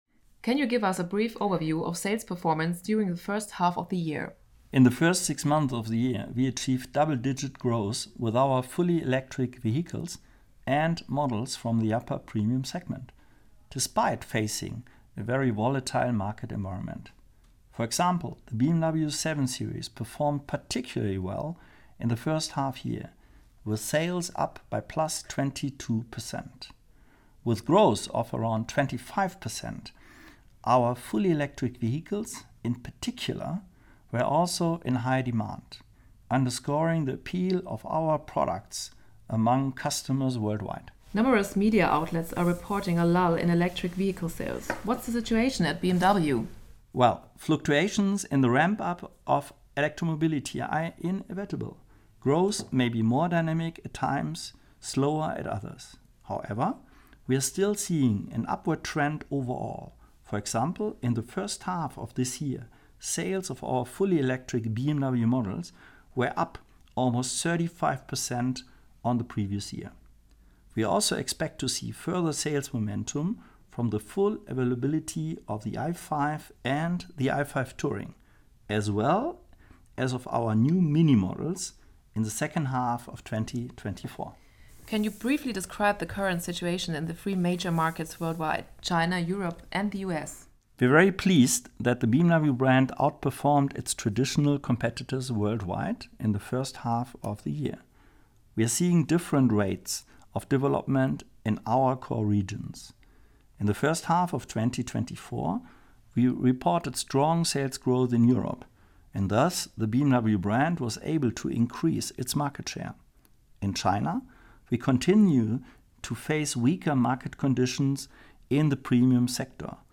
English-speaking podcast